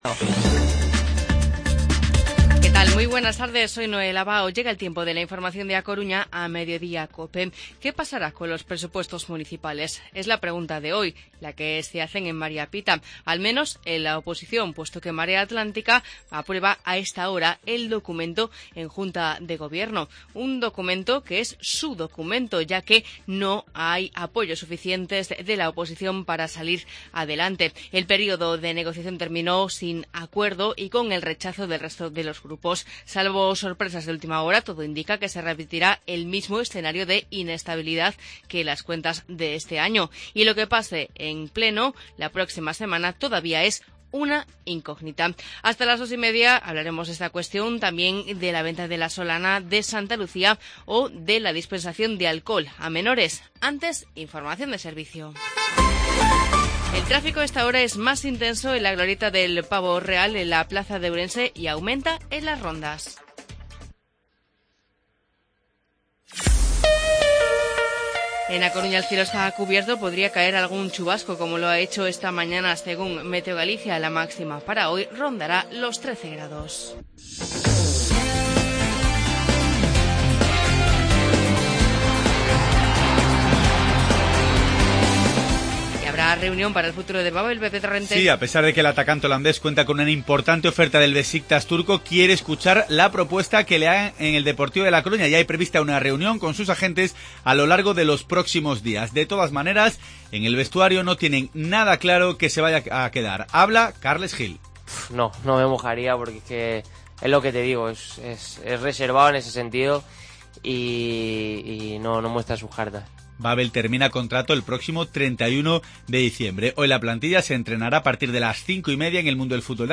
Informativo Mediodía Cope Coruña martes, 20 de diciembre de 2016